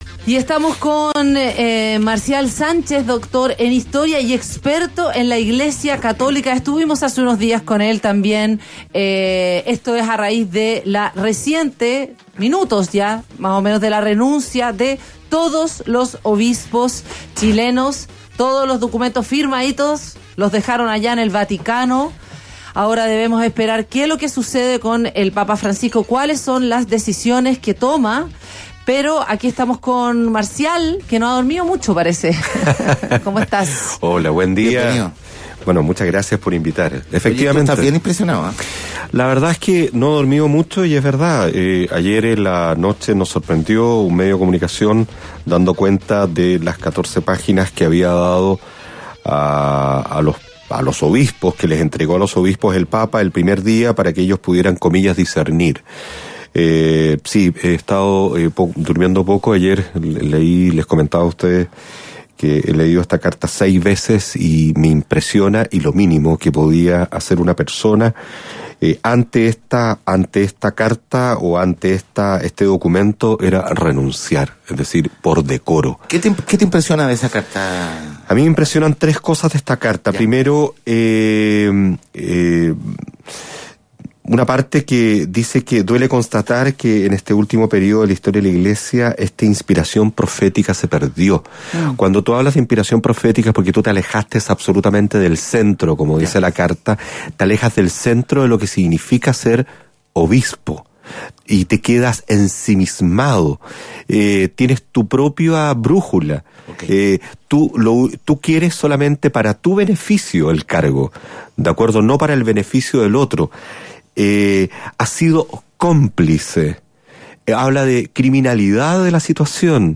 Escucha entrevista de Palabra Que Es Noticia.